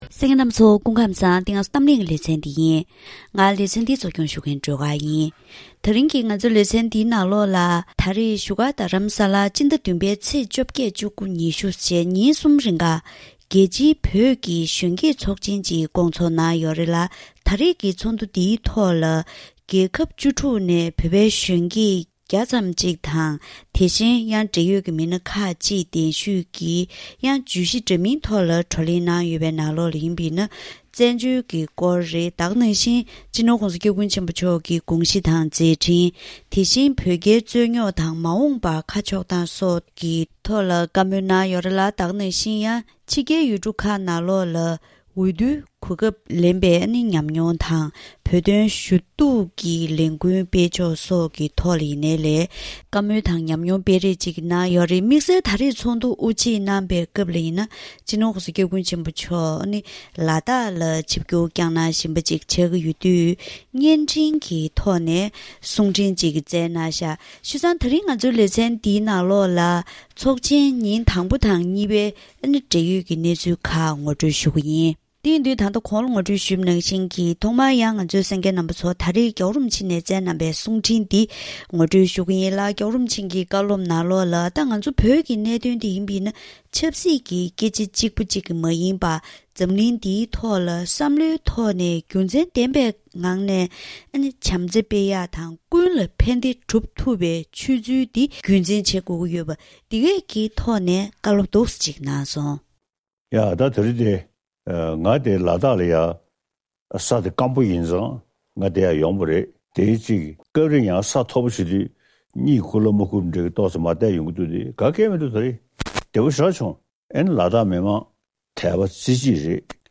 ཚོགས་འདུ་དབུ་འབྱེད་སྐབས་སྤྱི་ནོར་༧གོང་ས་སྐྱབས་མགོན་ཆེན་པོ་མཆོག་ནས་བཀའ་སློབ་སྩལ་བ།